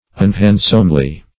[1913 Webster] -- Un*hand"some*ly, adv.